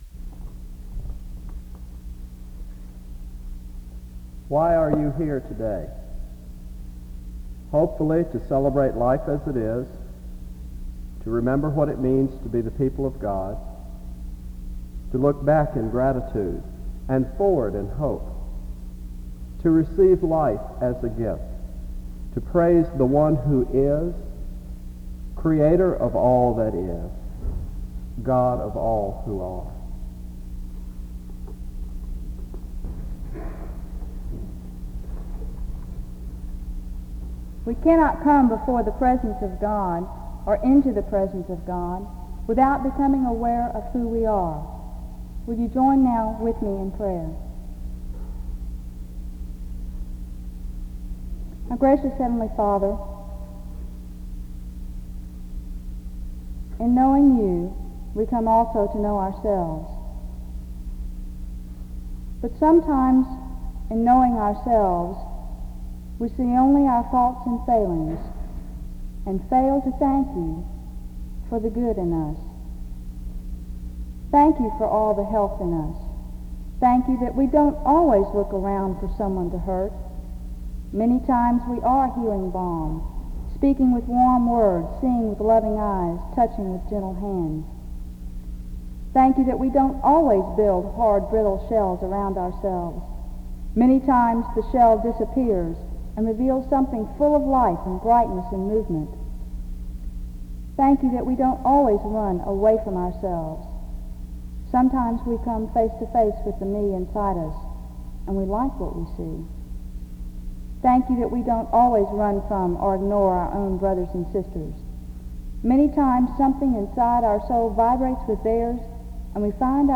The service begins with a brief introduction and prayer (0:00-3:03). The prodigal son parable is read from Luke 15:11-24 (3:04-5:20).